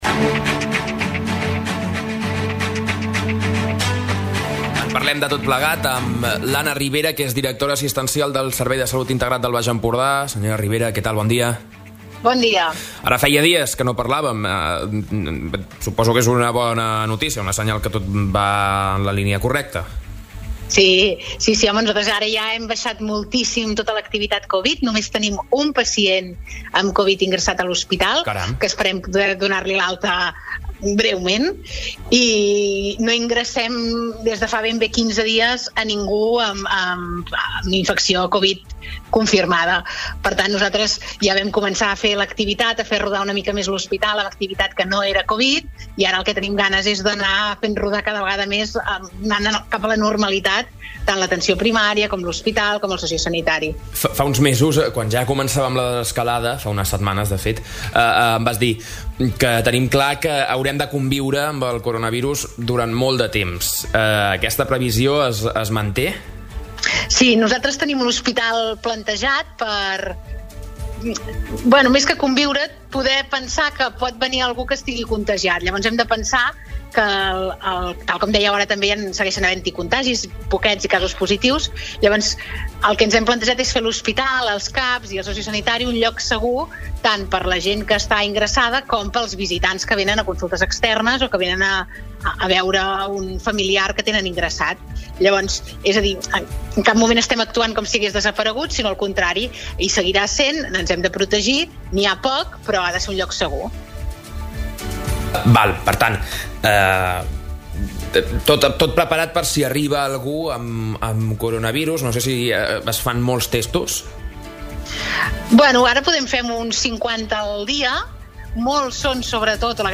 Entrevistes SupermatíPalamós